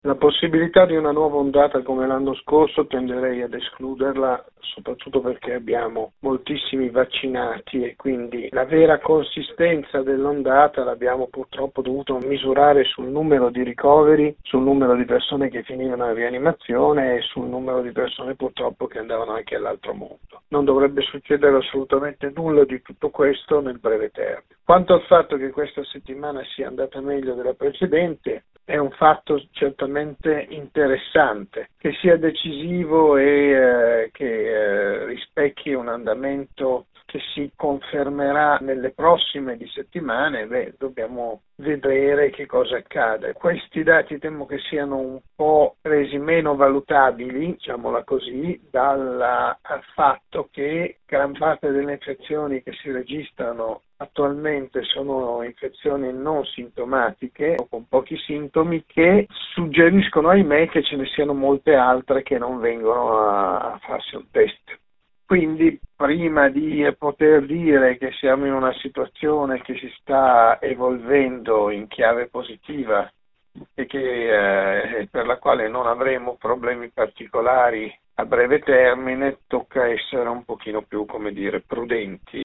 Possiamo quindi essere ottimisti o ci sarà un’altra ondata come quella dello scorso autunno? Lo abbiamo chiesto a Massimo Galli, direttore di Malattie infettive dell’ospedale Sacco di Milano: